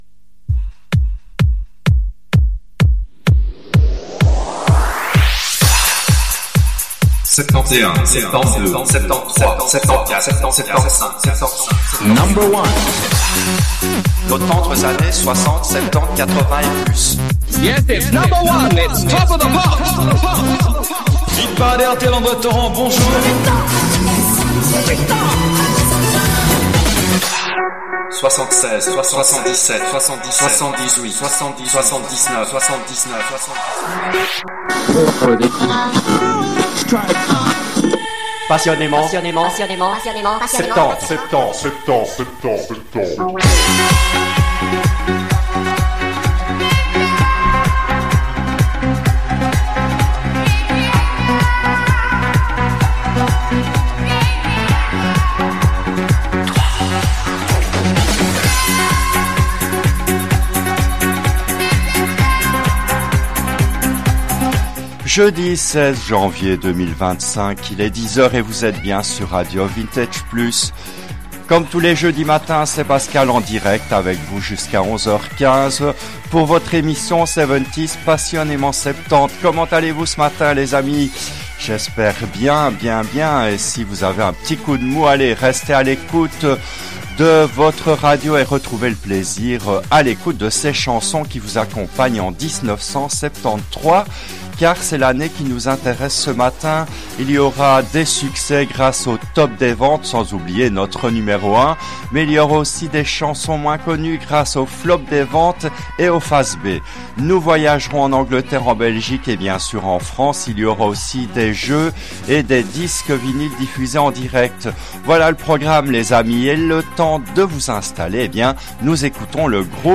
Cette émission a été diffusée en direct le jeudi 26 janvier 2023 à 10h, depuis les studios belges de RADIO VINTAGE PLUS.